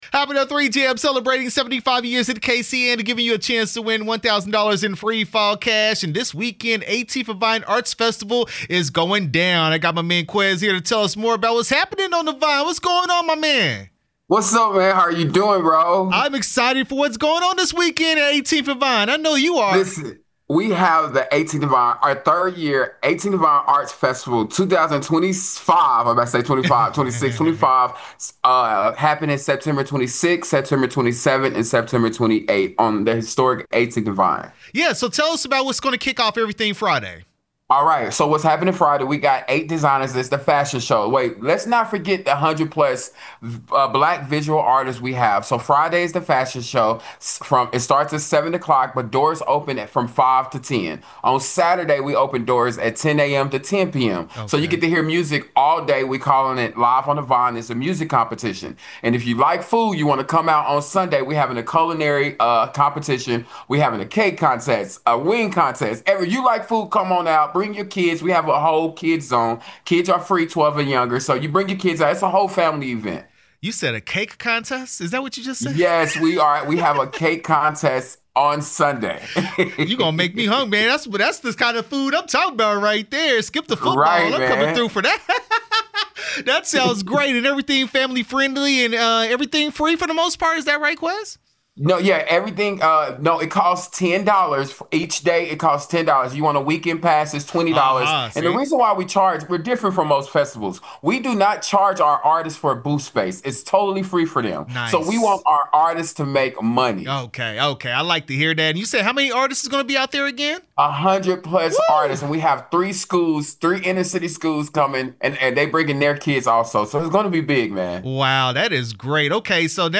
18th & Vine Arts Festival interview 9/25/25